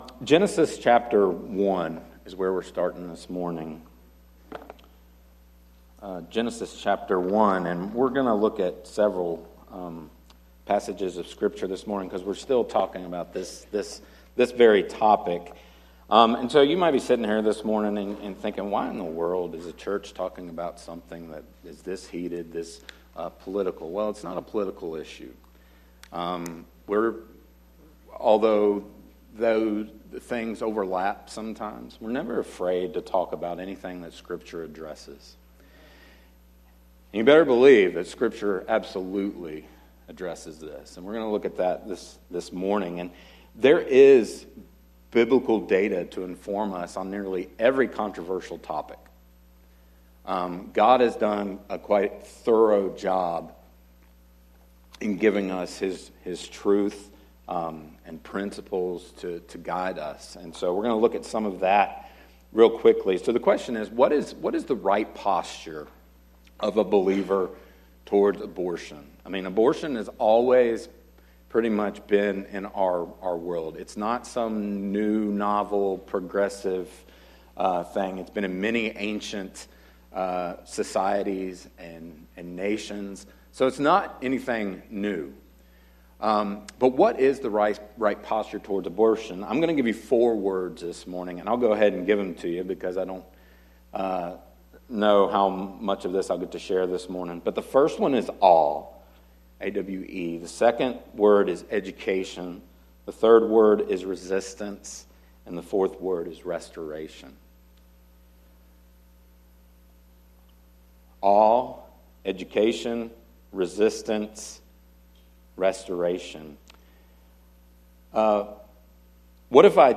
sermon-audio-trimmed-2.mp3